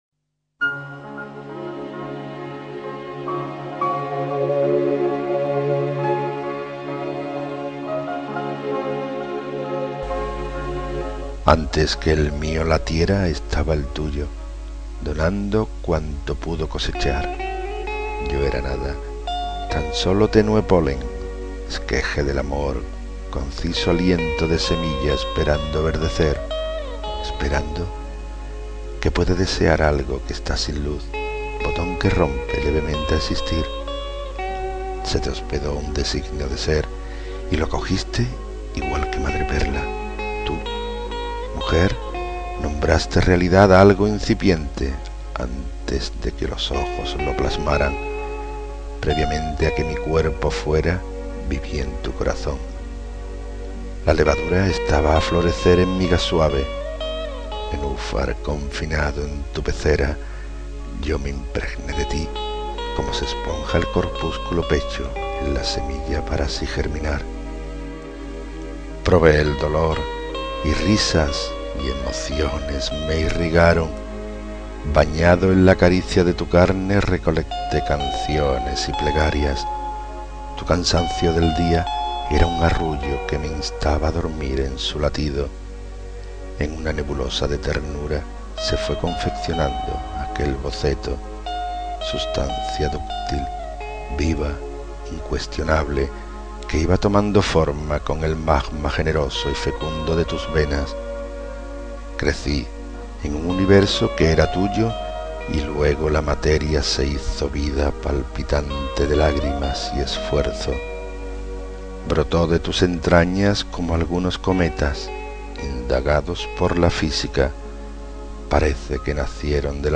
Inicio Multimedia Audiopoemas Barca sin puerto.